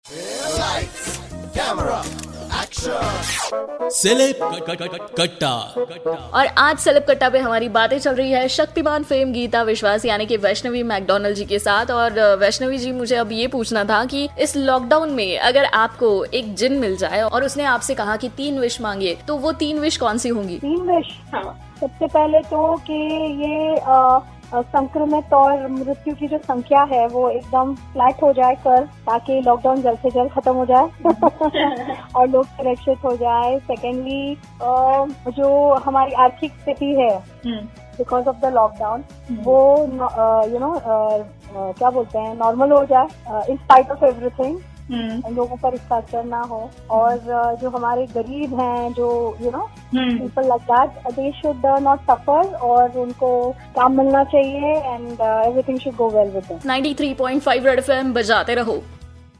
In this interview she shared her 3 wishes..